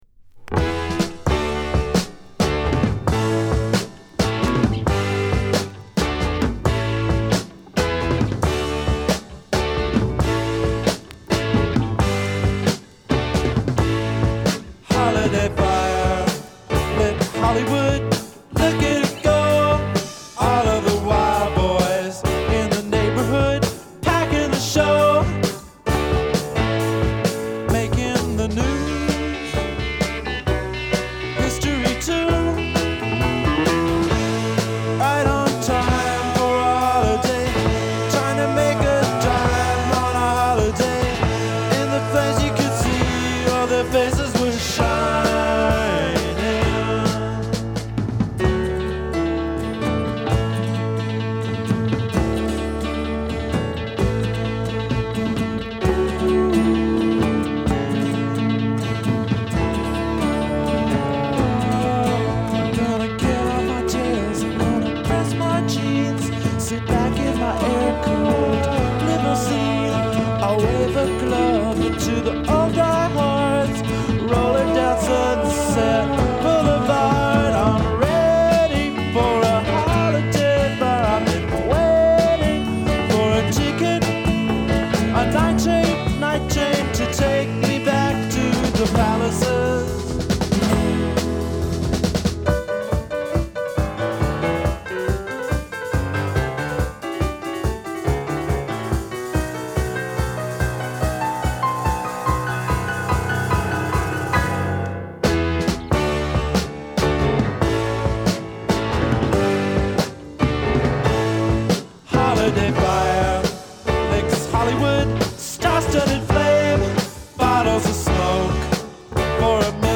ポップ・ロック・ダブルサイダー、基本の一枚。